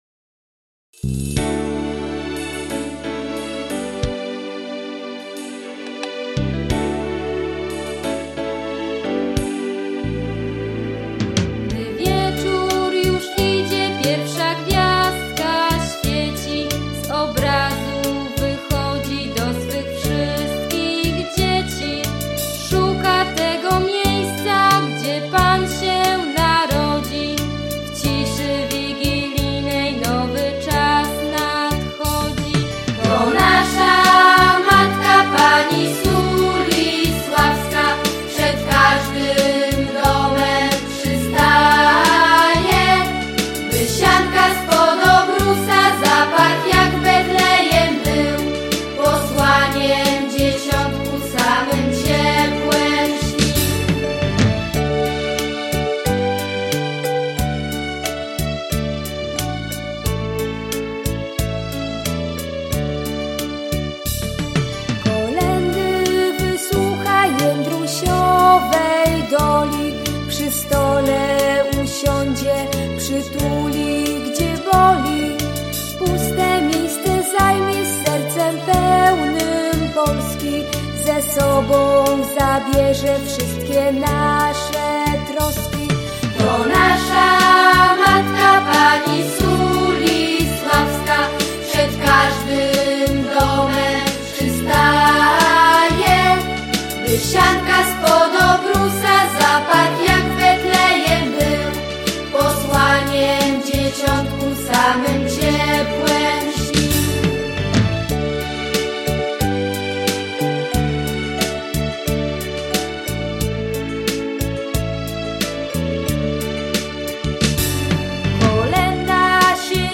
koleda_sulislawska.mp3